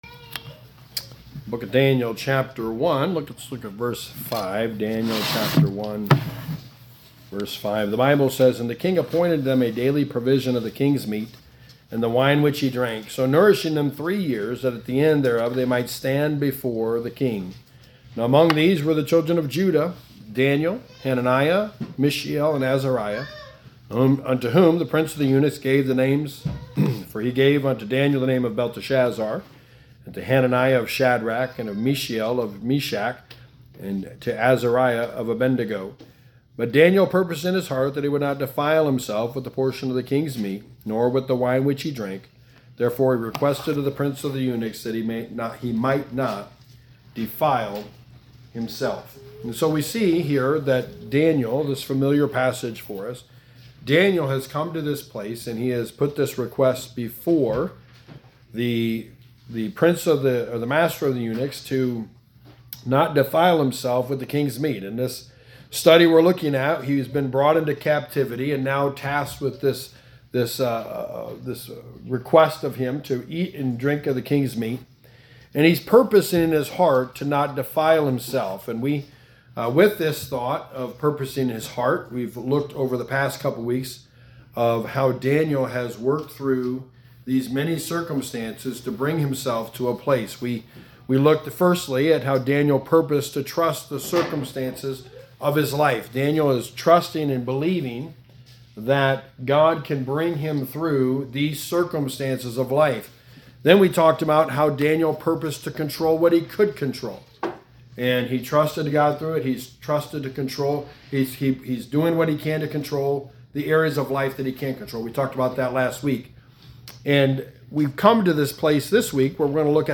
Walking With Daniel – A study of the life of Daniel / Sermon #5: A Purposed Life – Limiting Worldly Influences
Service Type: Sunday Morning